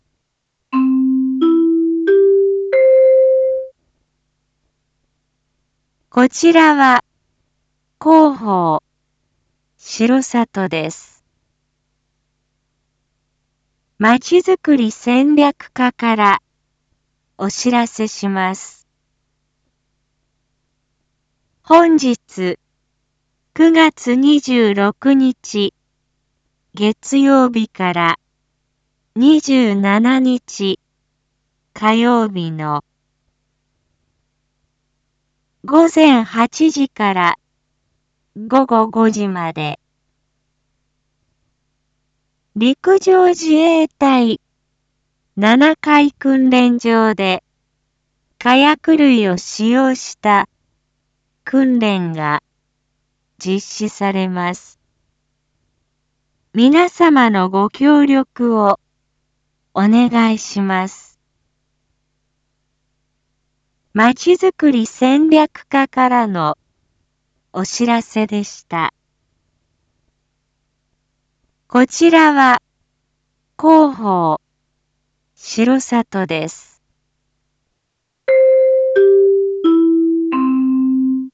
Back Home 一般放送情報 音声放送 再生 一般放送情報 登録日時：2022-09-26 07:01:22 タイトル：R4.9.26 7時放送分 インフォメーション：こちらは広報しろさとです。